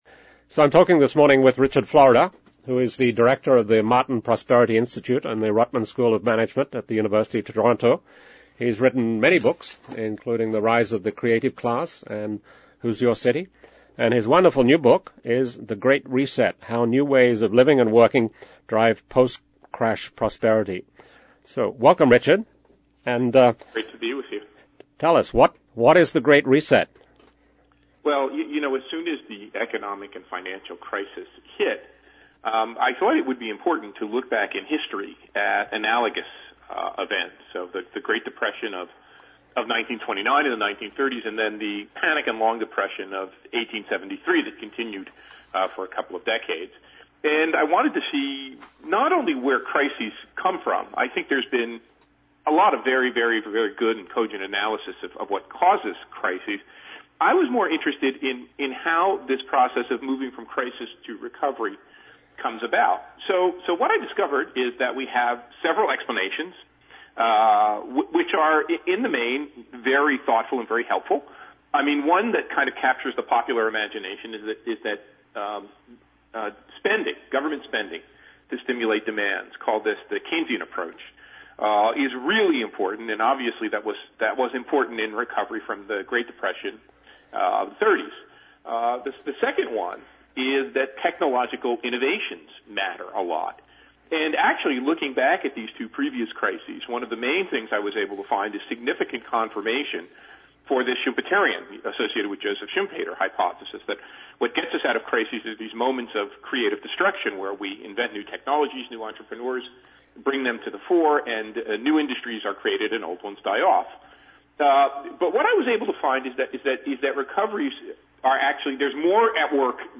The Great Reset: How New Ways of Living and Working Drive Post-Crash Prosperity by Richard Florida (Harper Business, 2010) The Great Reset: Richard Florida podcast I talked last week with Richard Florida about his book, The Great Reset. Richard is wonderfully passionate and articulate about what needs to happen after the financial meltdown of 2008. He sees that this is not about getting back to where we were.